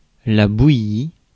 Ääntäminen
France Paris: IPA: /bu.ji/